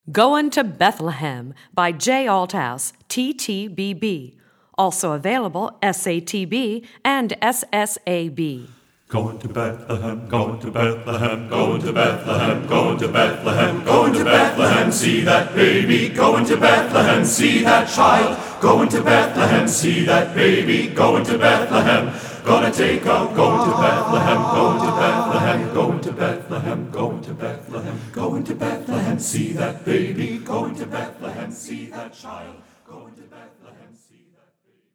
Voicing: TTBB